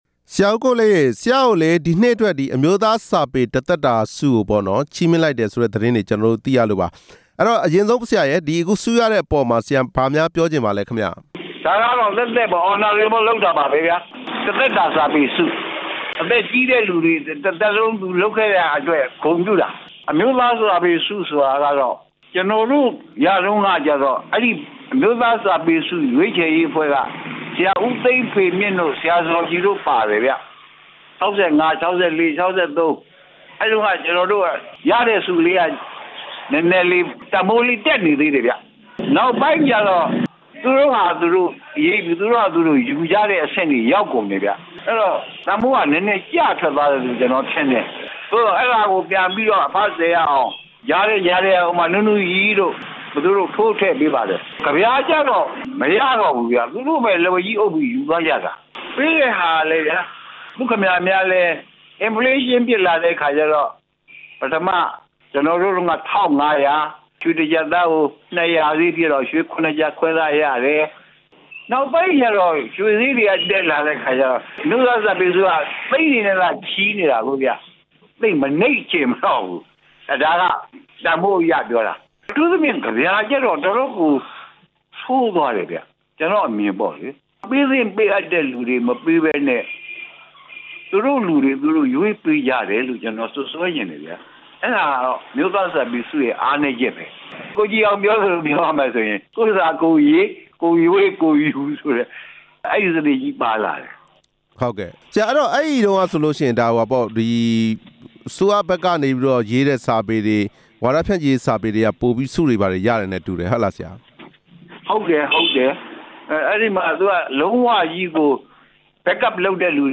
ကဗျာဆရာကြီး ကိုလေး-အင်းဝ ဂုဏ်ရည်နဲ့ မေးမြန်းချက်